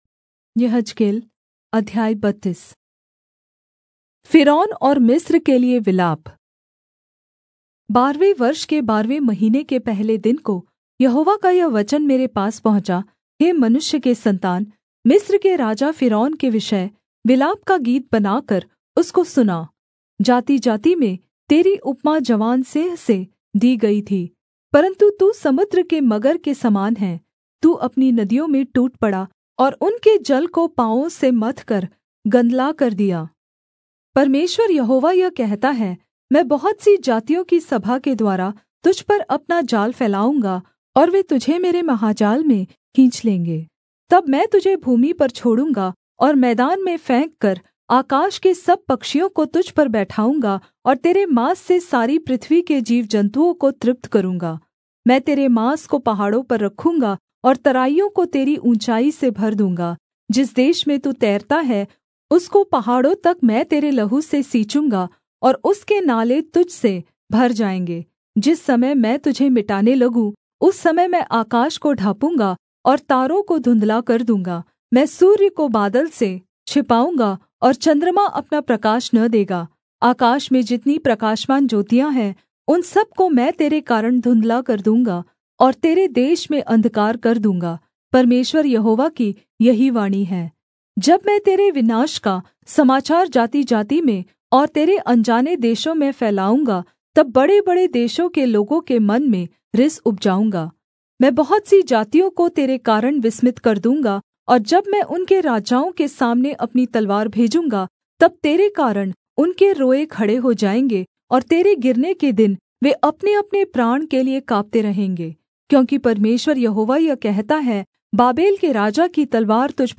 Hindi Audio Bible - Ezekiel 11 in Irvhi bible version